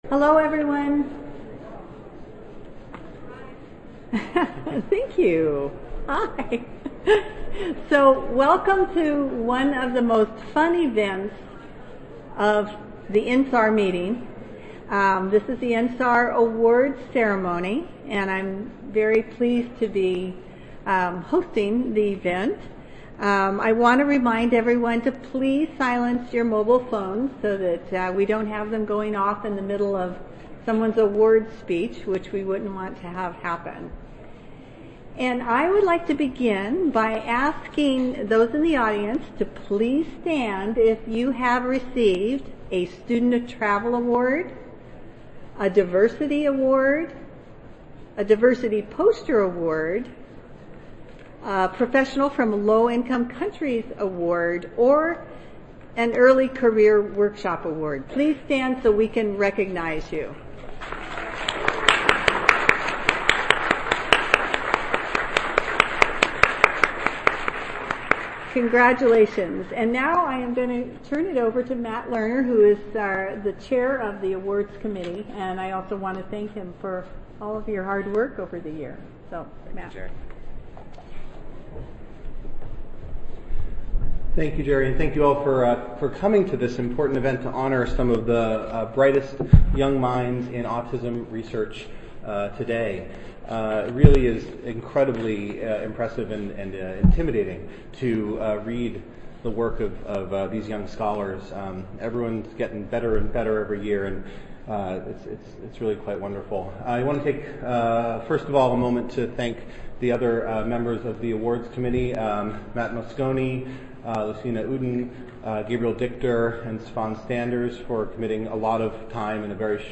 2017 International Meeting for Autism Research: INSAR Awards Ceremony
Yerba Buena 8-9 (Marriott Marquis Hotel)
Recorded Presentation